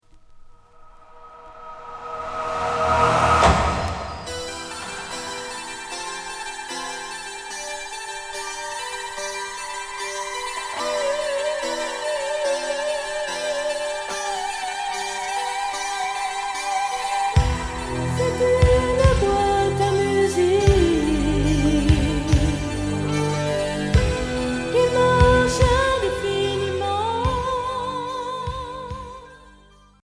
Pop FM progressif